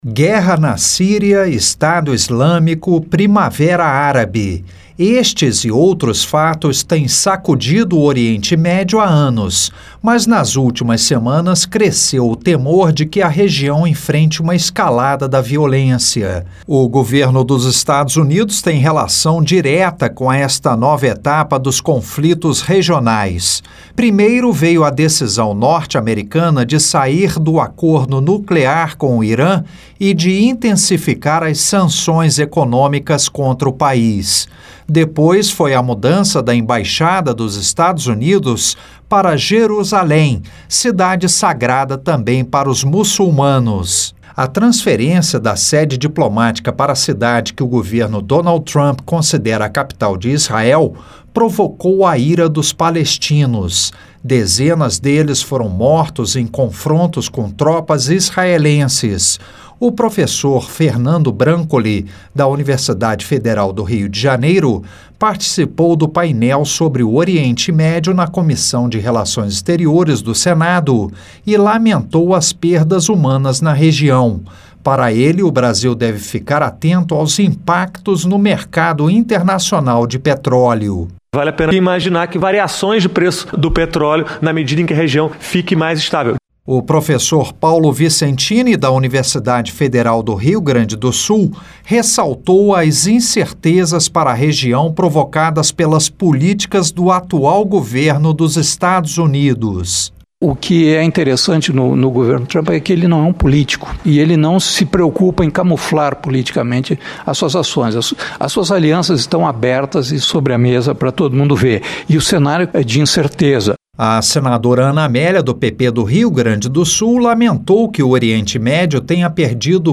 LOC: A AUDIÊNCIA PÚBLICA OCORREU NA COMISSÃO DE RELAÇÕES EXTERIORES DO SENADO.